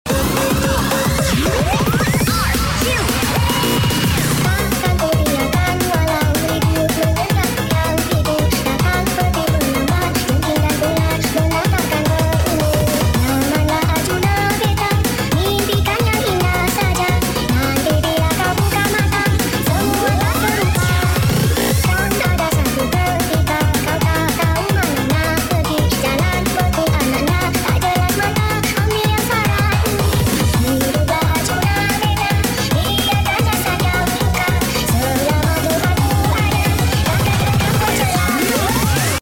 Gasing Penghapus Sound Effects Free Download